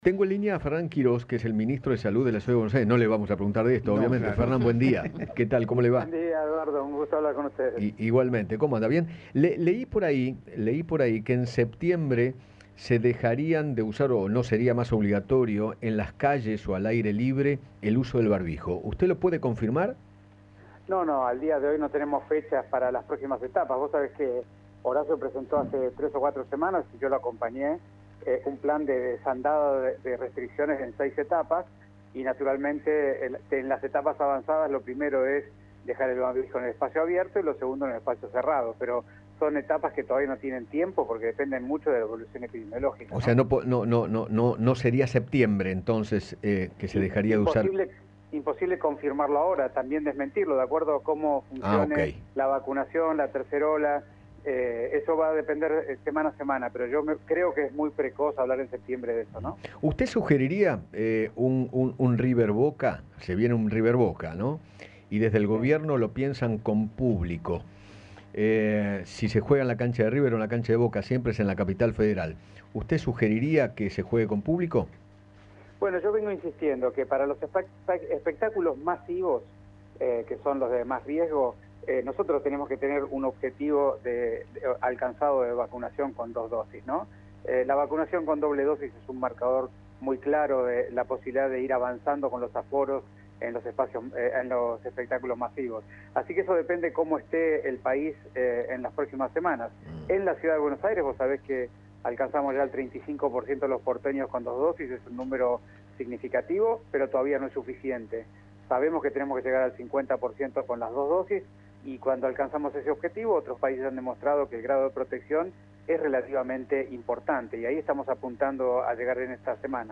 Fernán Quirós, ministro de Salud de la Ciudad de Buenos Aires, conversó con Eduardo Feinmann acerca de la posible llegada de una nueva ola de coronavirus, la importancia de terminar de vacunar para que su impacto sea leve, y se refirió al regreso de eventos masivos.